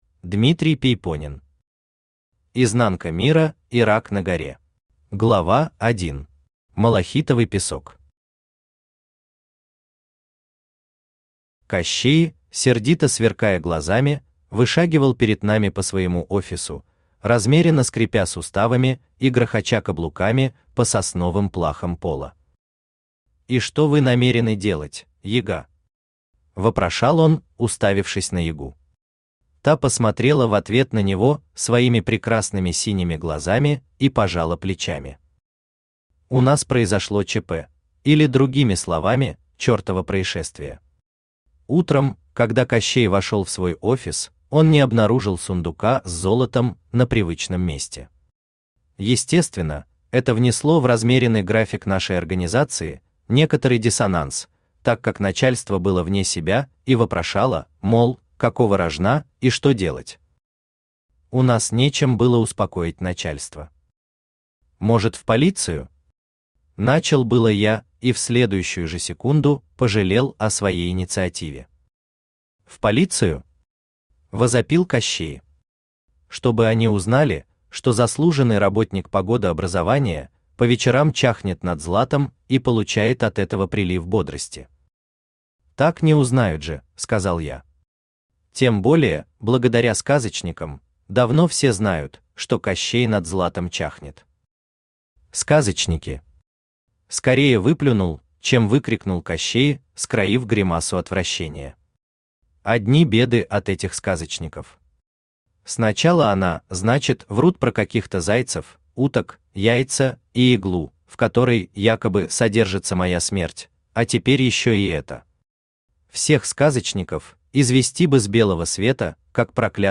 Аудиокнига Изнанка мира и рак на горе | Библиотека аудиокниг
Aудиокнига Изнанка мира и рак на горе Автор Дмитрий Пейпонен Читает аудиокнигу Авточтец ЛитРес.